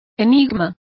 Complete with pronunciation of the translation of mystery.